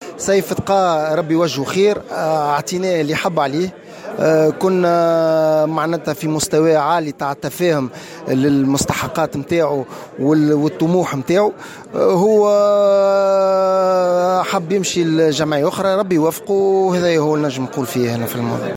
على هامش الندوة الصحفية المنعقدة اليوم الثلاثاء 24 جويلية 2018